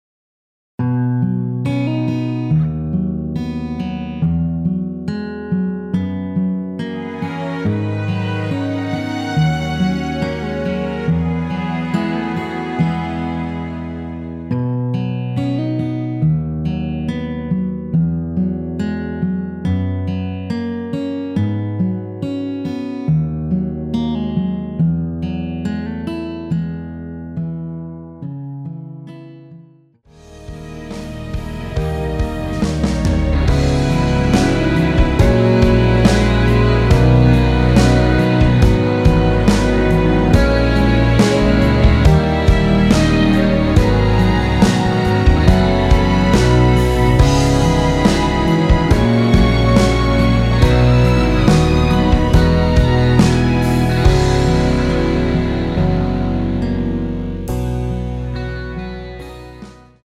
원키에서(-3)내린 MR 입니다.
앞부분30초, 뒷부분30초씩 편집해서 올려 드리고 있습니다.
중간에 음이 끈어지고 다시 나오는 이유는